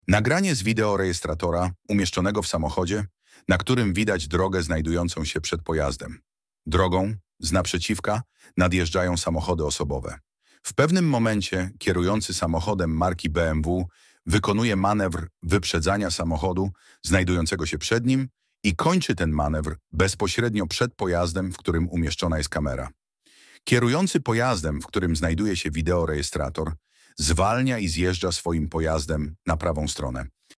Nagranie audio Audiodeskrypcja do nagrania